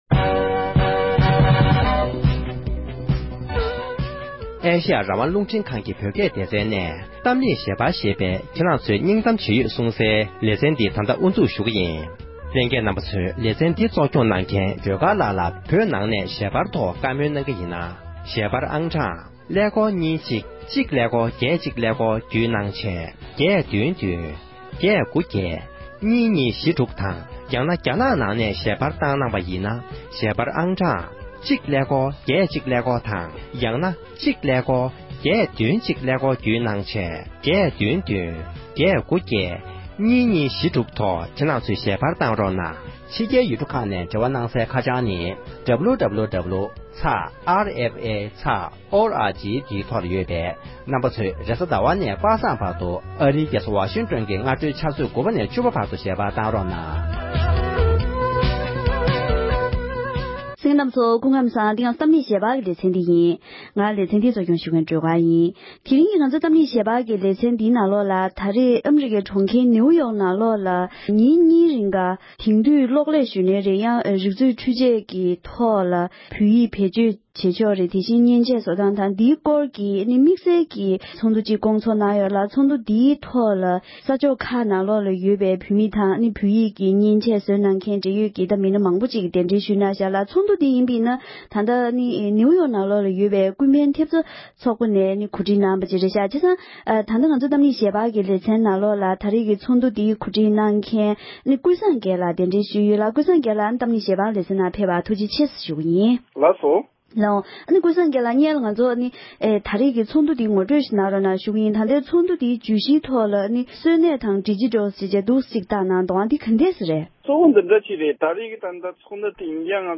ནིའུ་ཡོརྐ་ཏུ་སྐོང་ཚོགས་གནང་བའི་བོད་ཡིག་གི་བསྙེན་ཆས་དང་གློག་ཀླད་ཐོག་བོད་ཡིག་བེད་སྤྱོད་བྱ་ཕྱོགས་ཀྱི་ཚོགས་འདུའི་སྐོར་བགྲོ་གླེང༌།